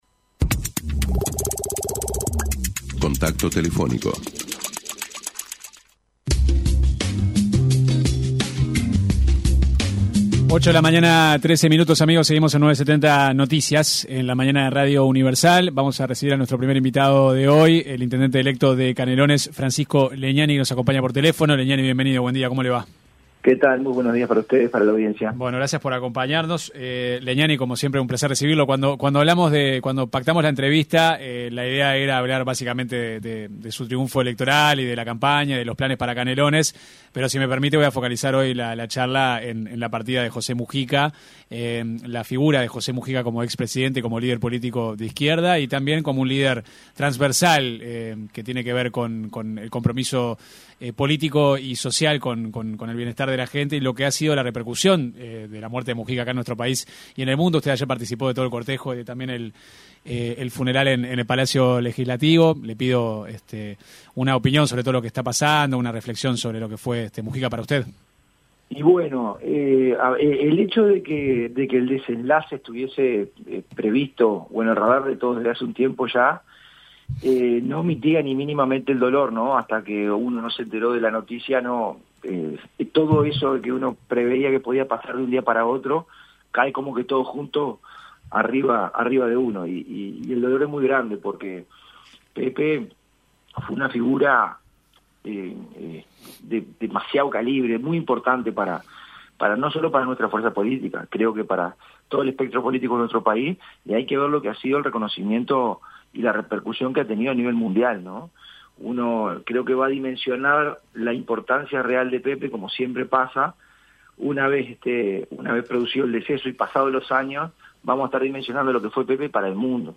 El intendente electo de Canelones, Francisco Legnani, se refirió en diálogo con 970 Noticias, a la importancia de la inserción internacional que Mujica brindó a Uruguay.